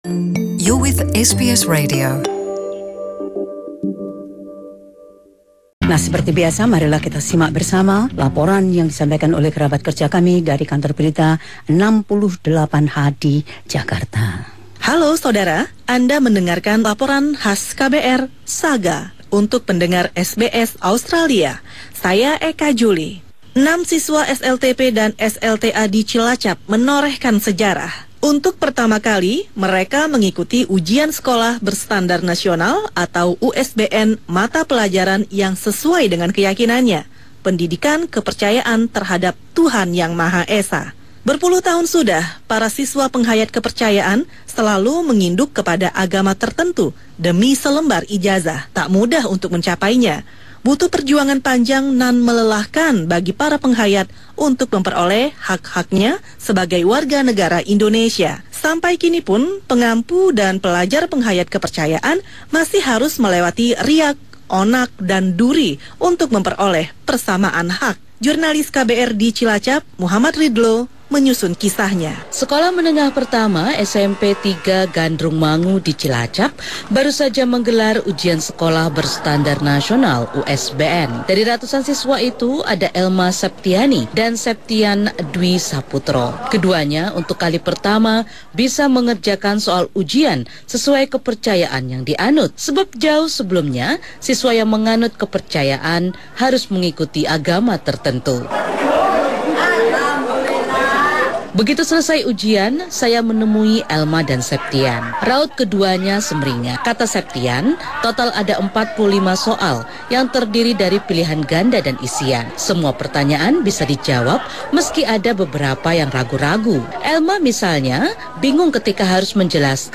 Laporan khusus tim KBR 68H menceritakan pengenalan Pendidikan Kepercayaan di Cilacap.